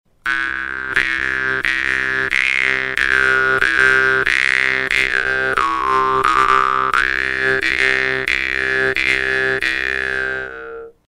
Die Wimmer-Bades Pro erinnert von der Form her an die sehr einfachen Modelle aus der gleichen Werkstatt, ihr Klang ist allerdings klar und die Stimmung sauber.
Ihre relativ straffe Zunge erzeugt einen lauten, scharfen Sound, der auch auf der Bühne mithalten kann.